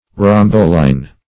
Search Result for " rombowline" : The Collaborative International Dictionary of English v.0.48: Rombowline \Rom*bow"line\, n. [Etymol. uncertain.]